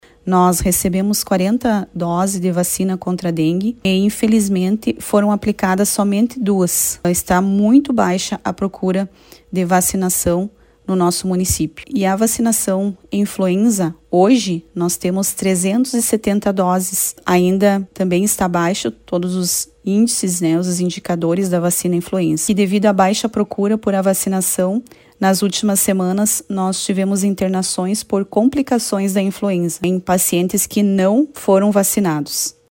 No município de Pejuçara, a secretária da Saúde, Eliana Moura, ressalta que é baixo o índice vacinal contra a gripe e contra a dengue. Abaixo, sonora de Eliana Moura.
03.-SONORA-ELIANA.mp3